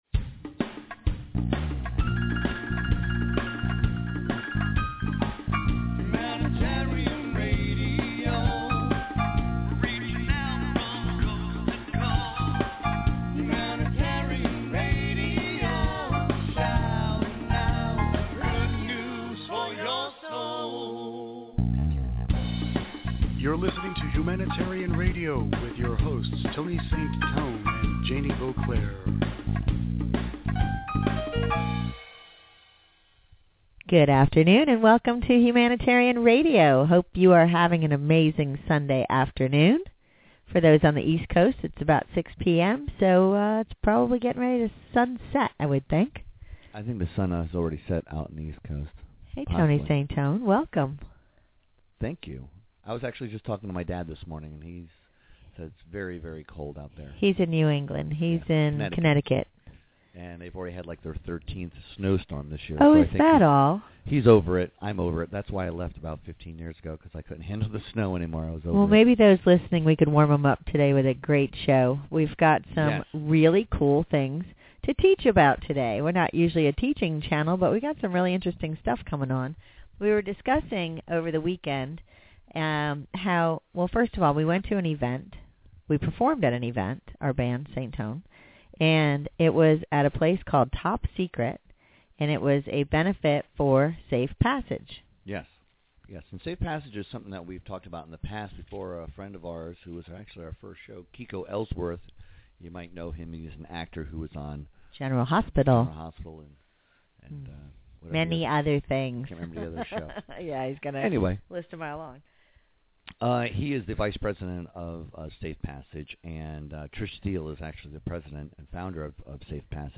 HR Interview